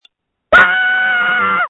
Screams from January 8, 2021
• When you call, we record you making sounds. Hopefully screaming.